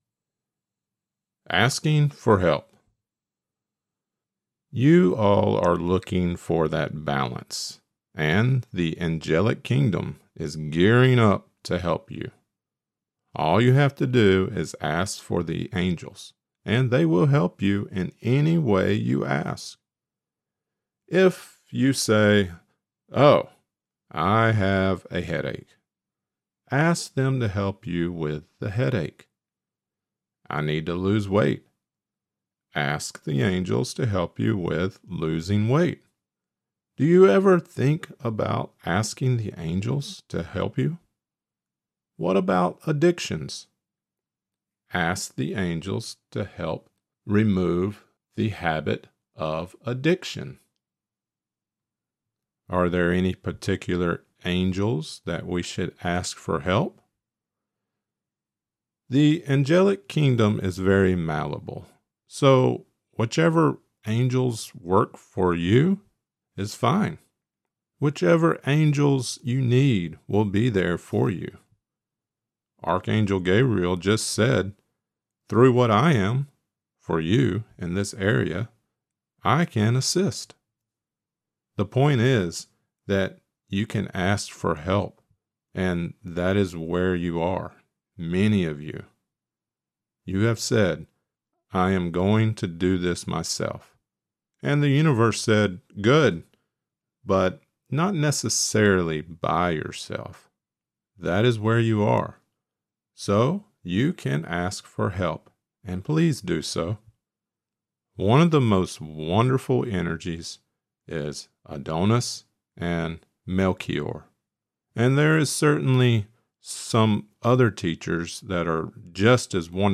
This lecture includes the channeling of Vywamus: * Asking for Help.